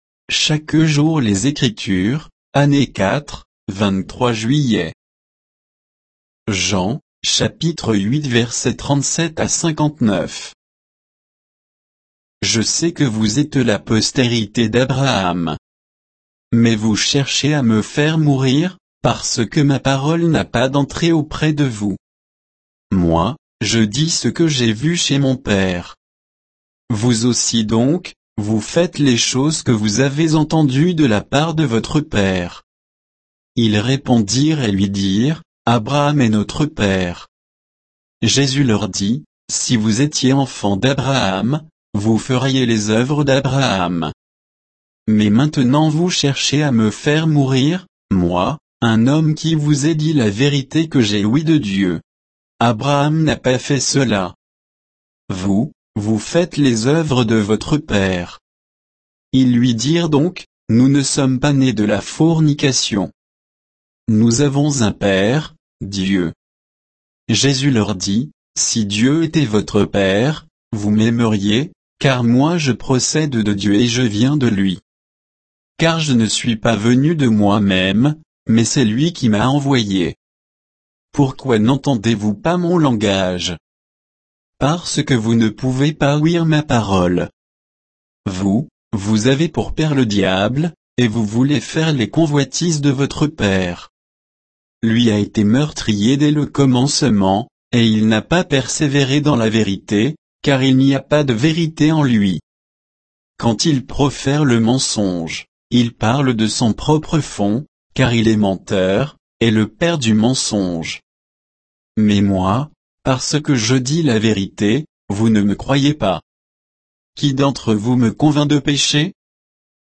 Méditation quoditienne de Chaque jour les Écritures sur Jean 8